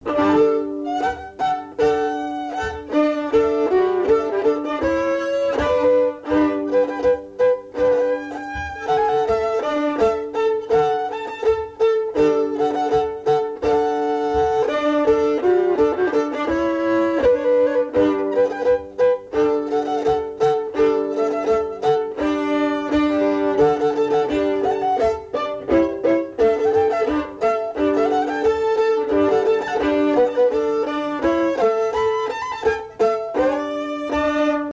Usage d'après l'analyste circonstance : fiançaille, noce
Pièce musicale inédite